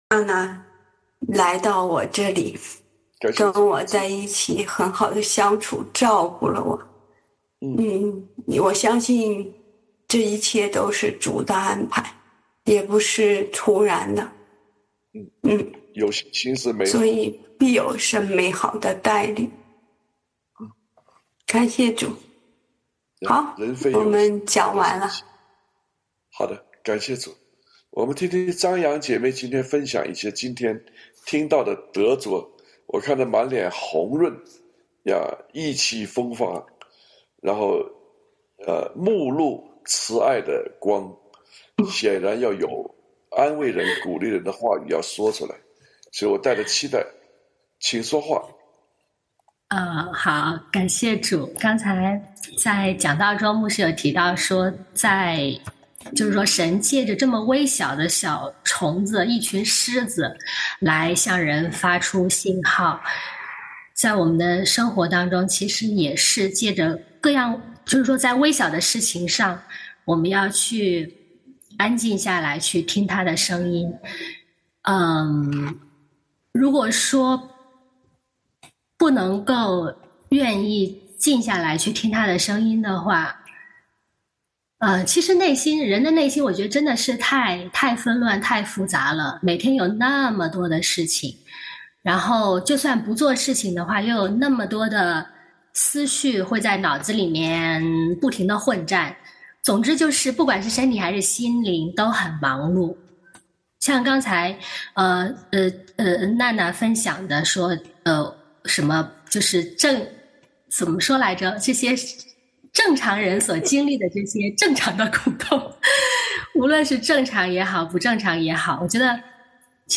出埃及记 出埃及记 8 ：16-19 第一场成人主日学（大洋洲亚洲场） 第二场成人主日学（北美欧洲场）